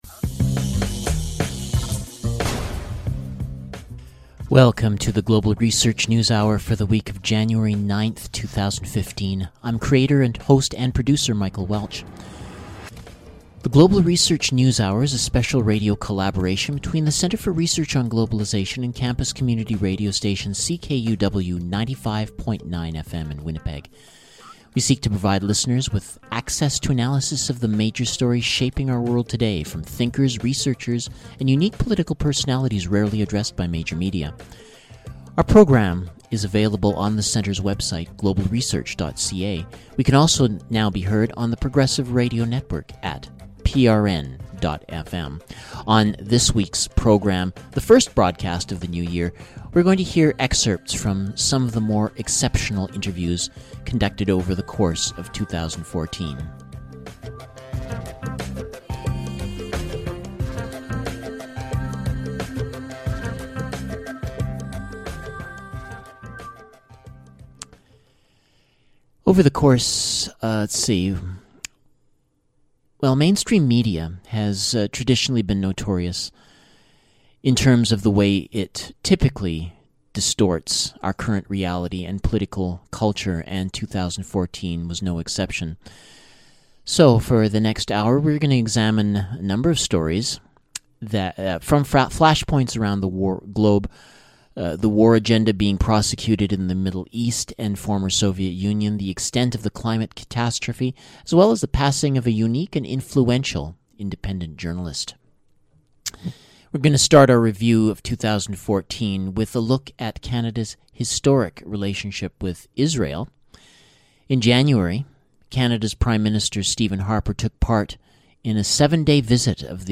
Excerpts from interviews from the programme over the past year